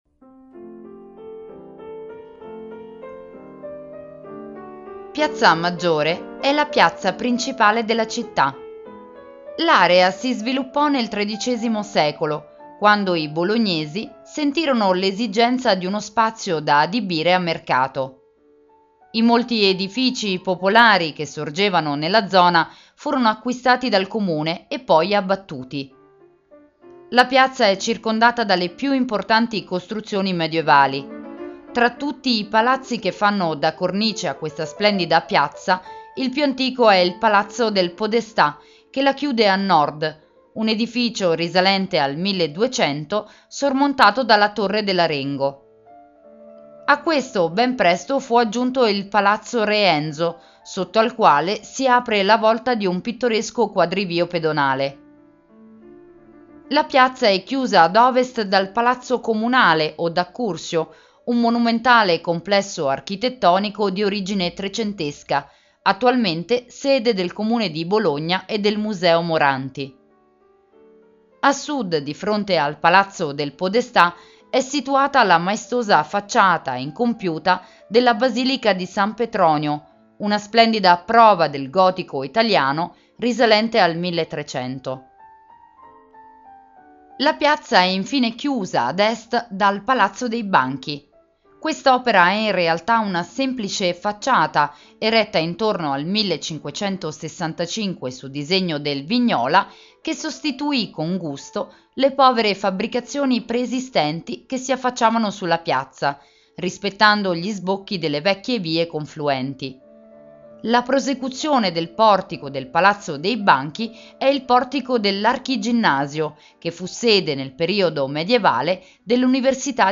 Audioguida Bologna - Piazza Maggiore - Audiocittà